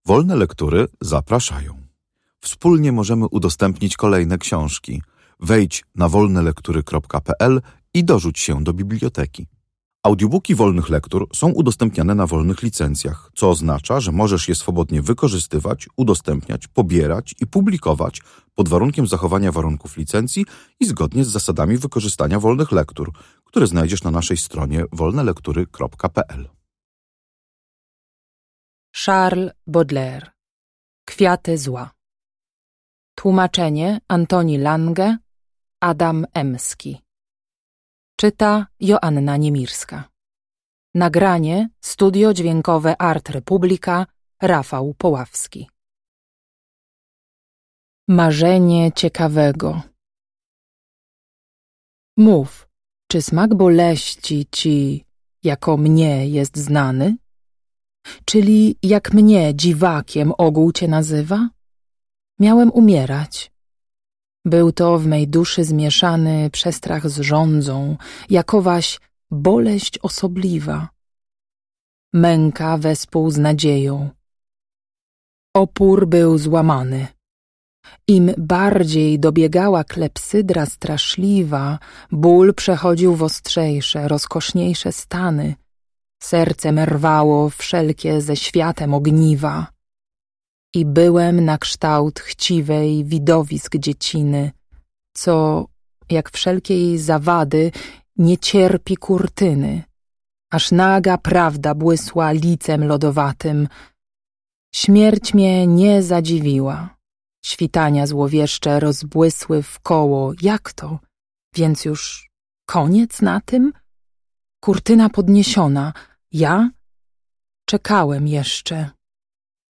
Sonet
Audiobook